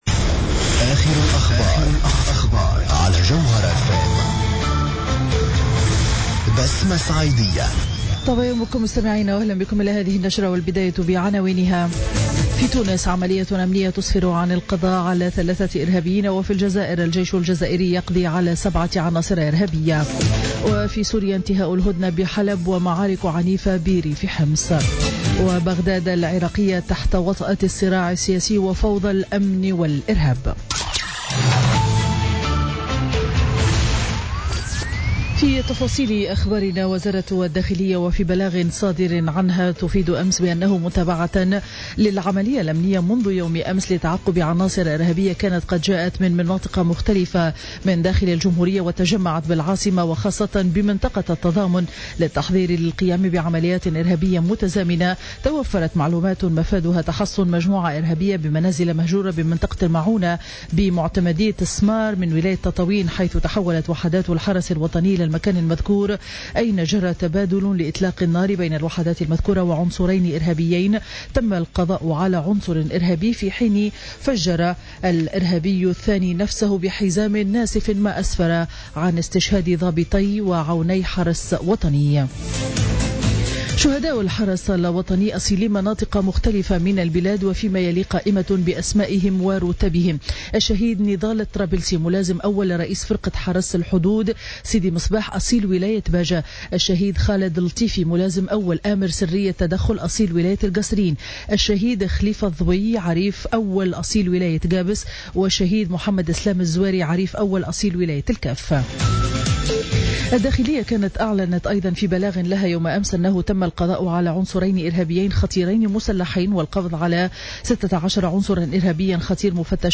نشرة أخبار السابعة صباحا ليوم الخميس 12 ماي 2016